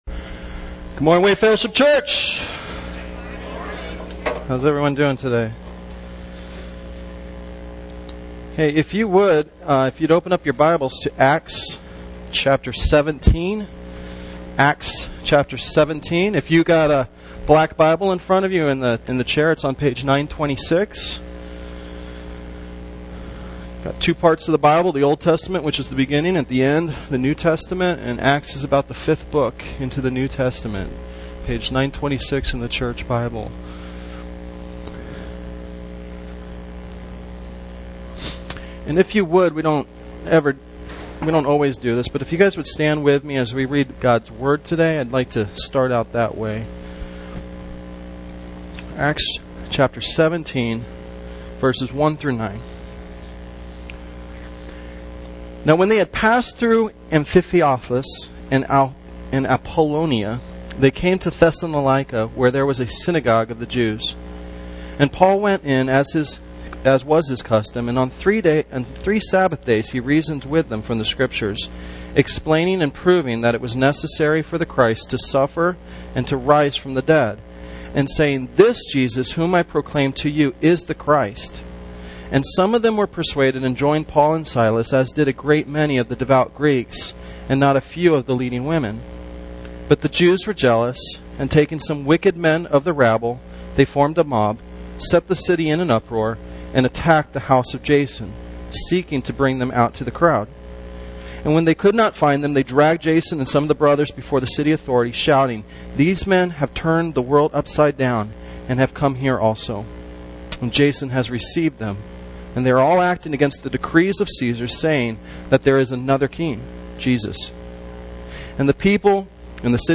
* We apologize, but there is a slight buzz throughout this recording.